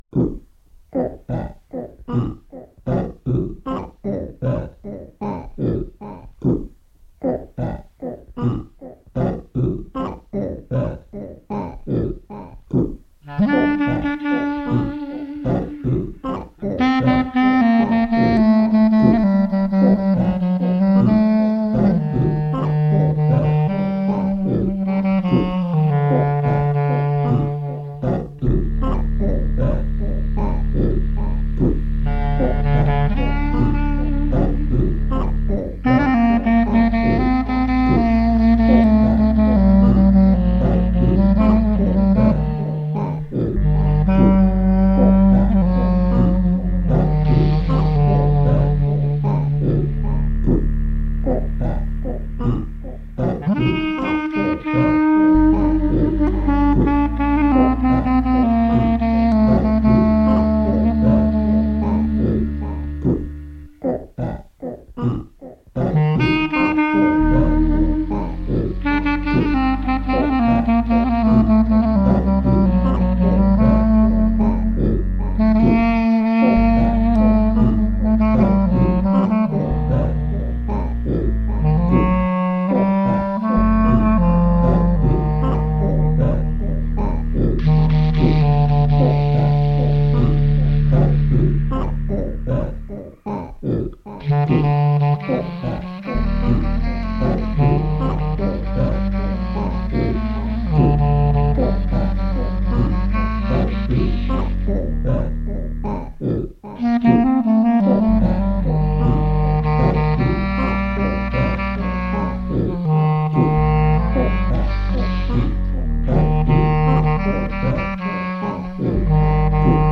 Recorded at home in Manhattan July 31, 2014
Bb clarinet, voice, FX
Stereo (Pro Tools)